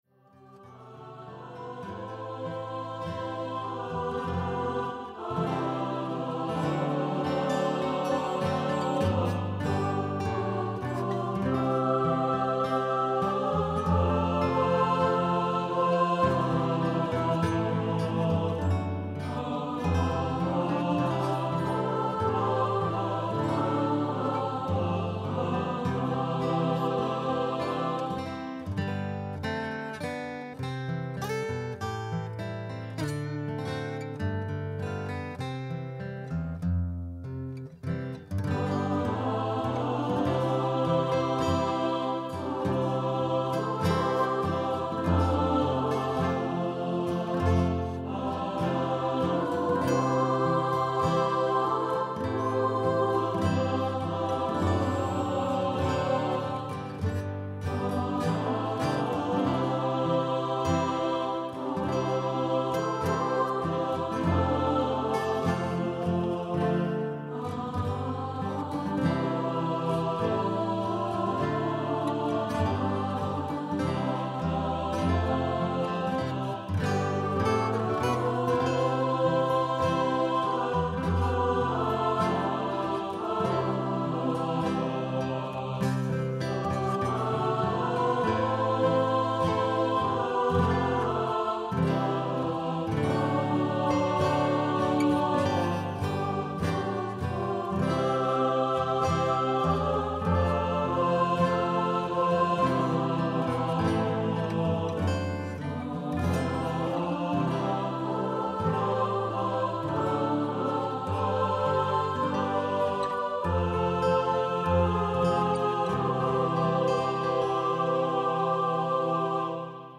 A 3 part vocal arrangement with guitar/piano/accompaniment.